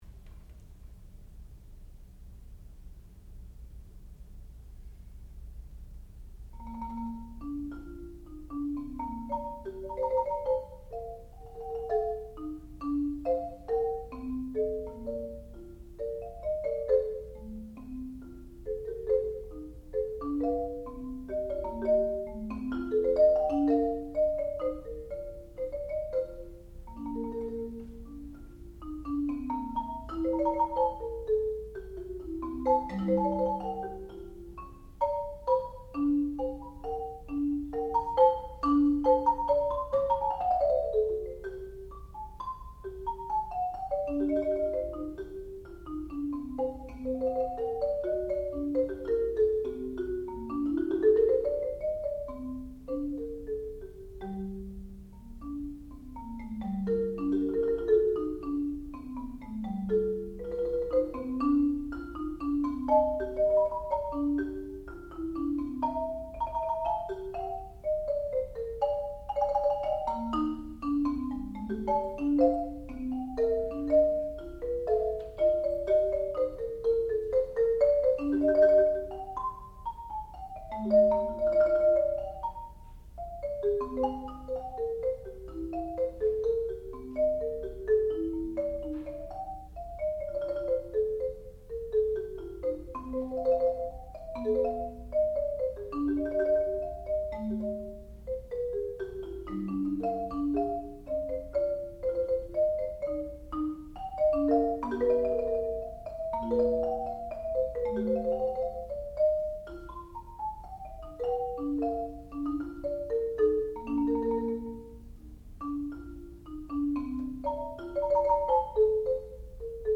sound recording-musical
classical music
percussion
Master Recital